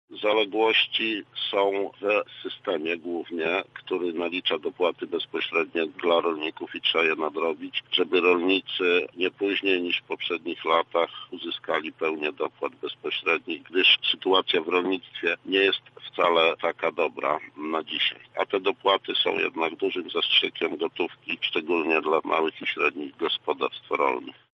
– W tej agencji jest dużo zaległości do nadrobienia i od tego chcę rozpocząć swoją działalność – mówi Krzysztof Gałaszkiewicz.